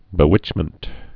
(bĭ-wĭchmənt)